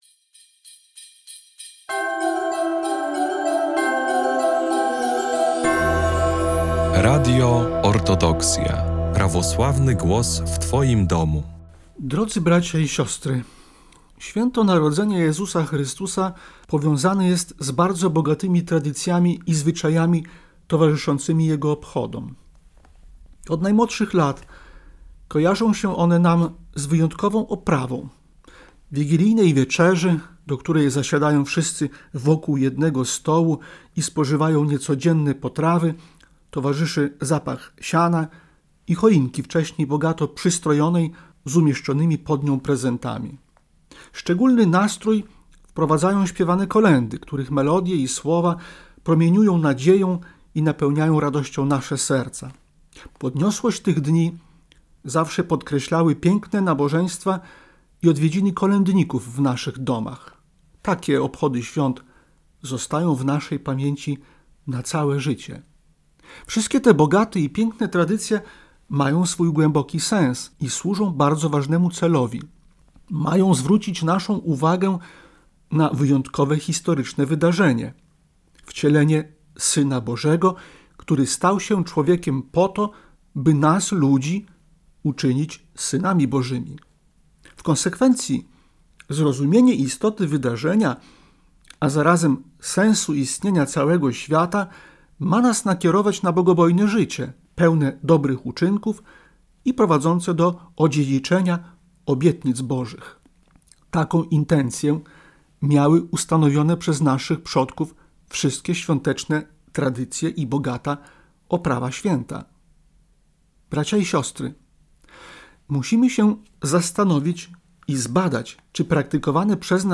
Orędzie Bożonarodzeniowe Arcybiskupa Jakuba 2026
Zapraszamy do wysłuchania bożonarodzeniowego orędzia Jego Ekscelencji Najprzewielebniejszego Jakuba Arcybiskupa Białostockiego i Gdańskiego.